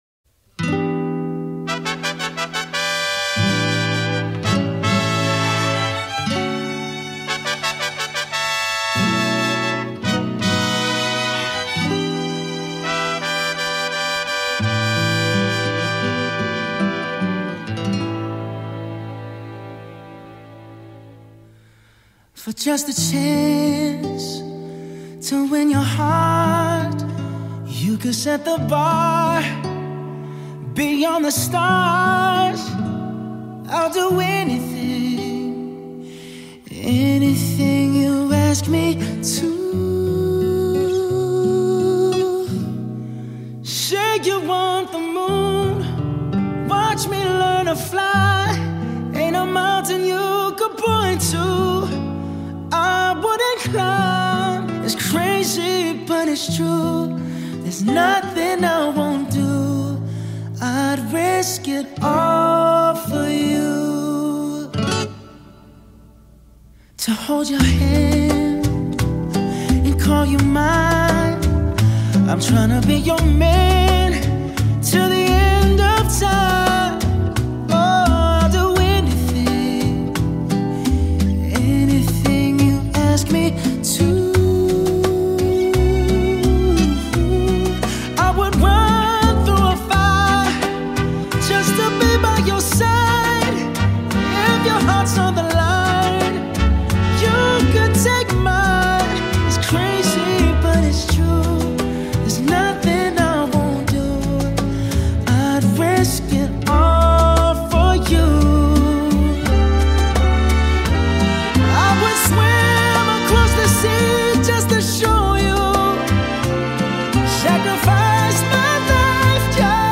smooth delivery